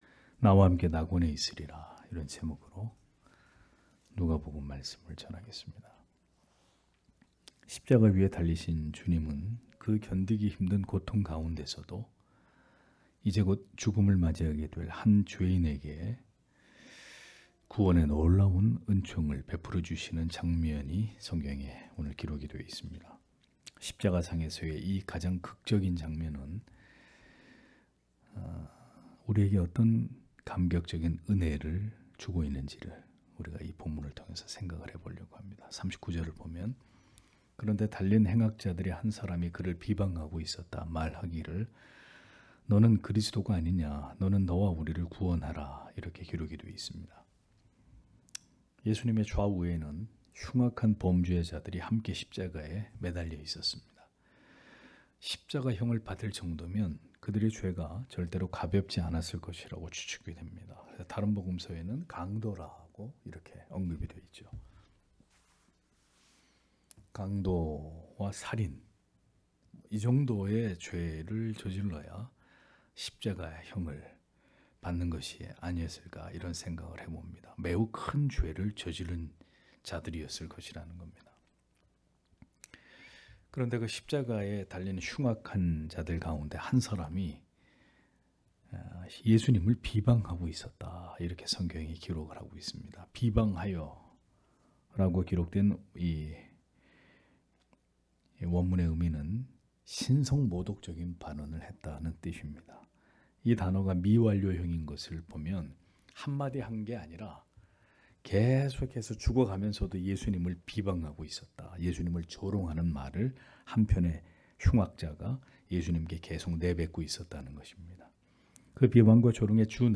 금요기도회 - [누가복음 강해 179] '나와 함께 낙원에 있으리라' (눅 23장 39- 43절)